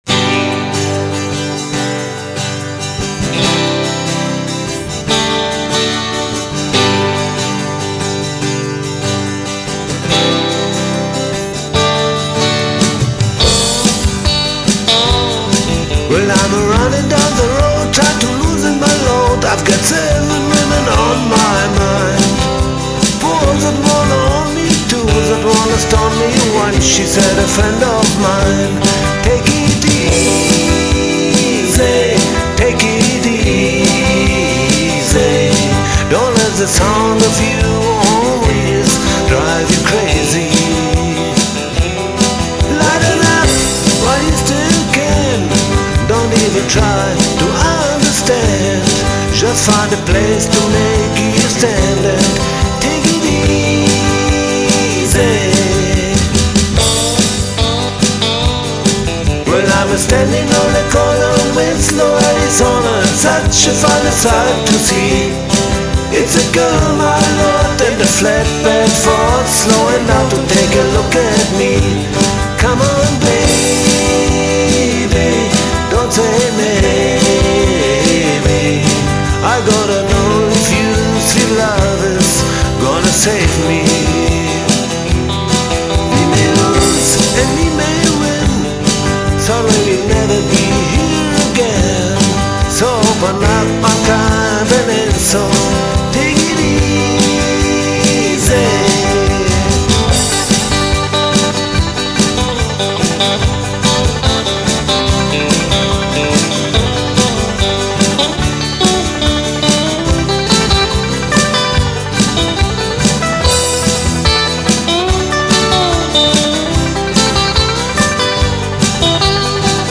Keyboards, Gesang
Bass
Schlagzeug, Gesang
Gitarren, Gesang